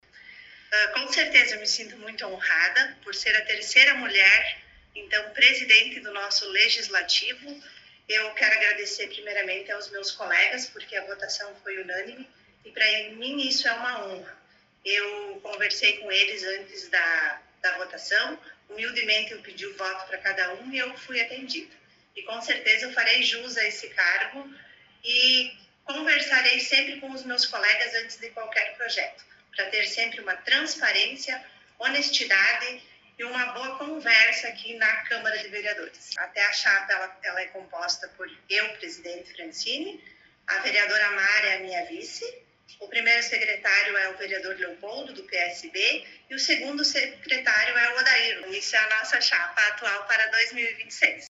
A nova Presidente do Poder Legislativo concedeu entrevista